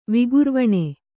♪ vigurvaṇe